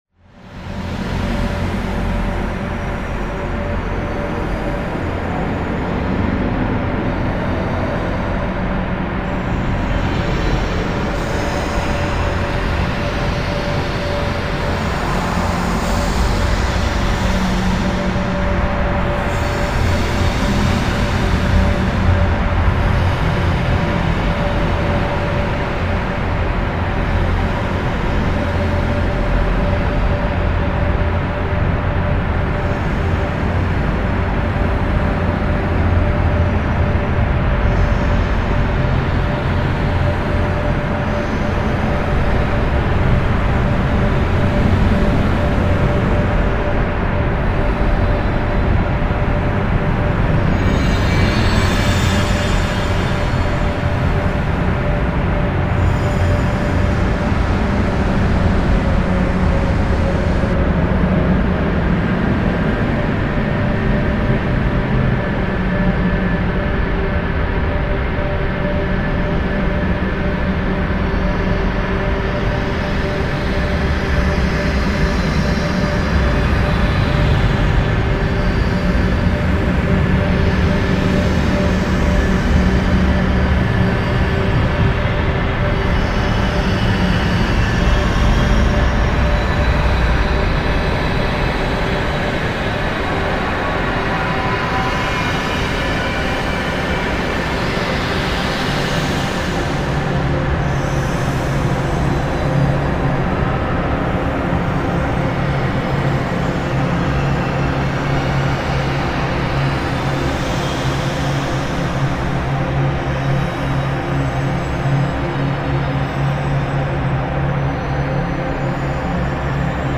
This is the sound of Apocalypse.
Now this is the true world of ambient and industrial.
Not pleasant music but surely all elementary stuff.